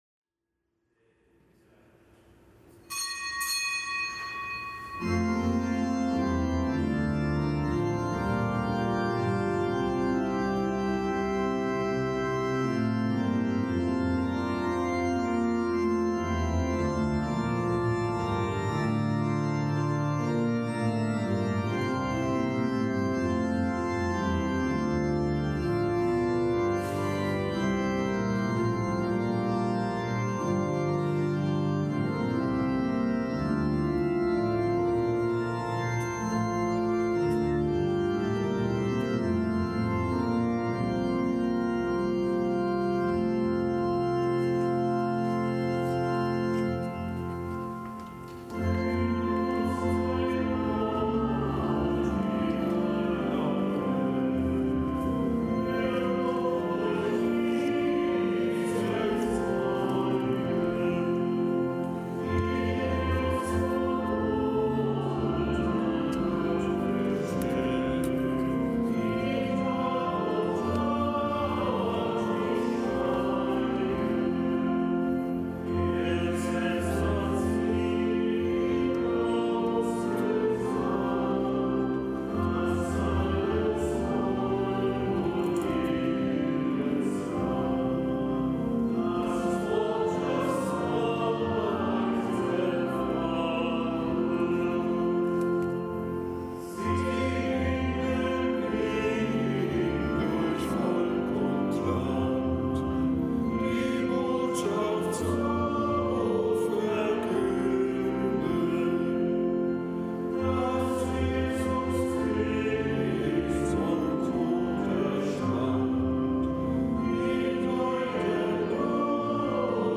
Kapitelsmesse aus dem Kölner Dom am Fest der Heiligen Simon und Judas. Zelebrant: Weihbischof Ansgar Puff.